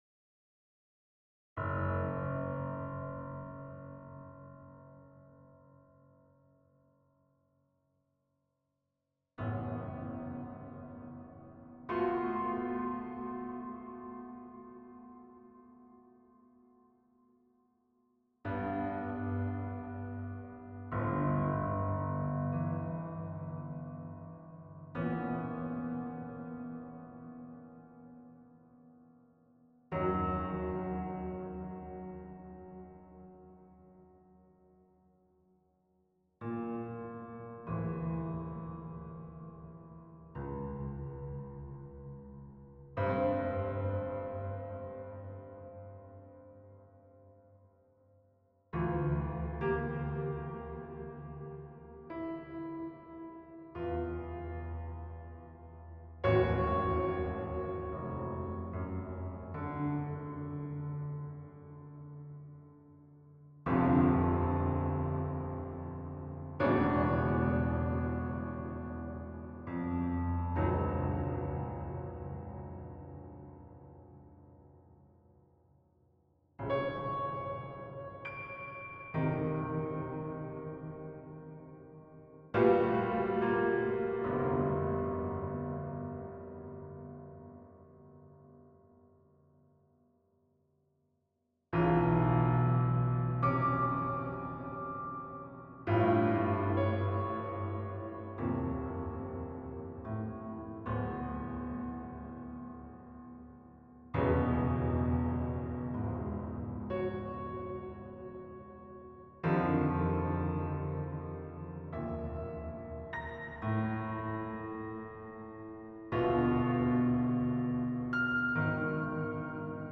Yamaha C7 Player
TrueReverbStereo: Cathedral